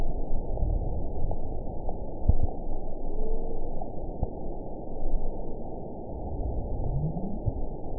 event 921873 date 12/20/24 time 08:09:15 GMT (11 months, 2 weeks ago) score 9.02 location TSS-AB03 detected by nrw target species NRW annotations +NRW Spectrogram: Frequency (kHz) vs. Time (s) audio not available .wav